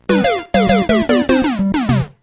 So klingt dein Block zuhause